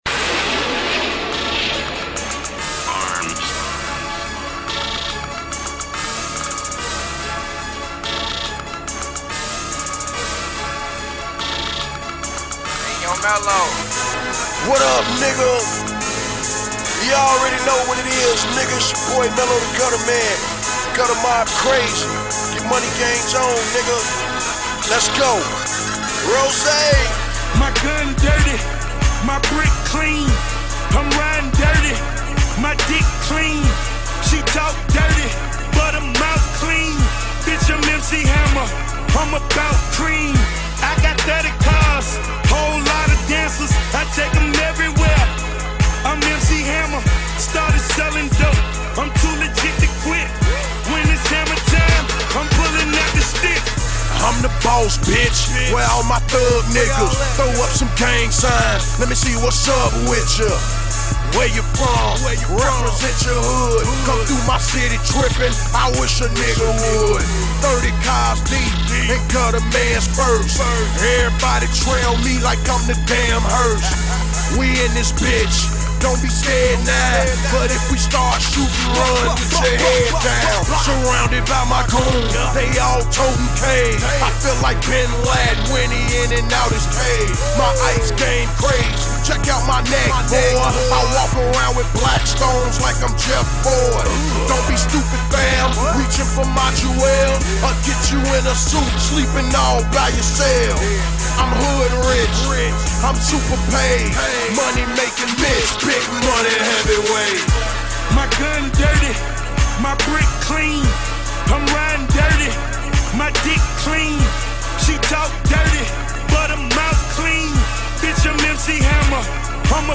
(Freestyle)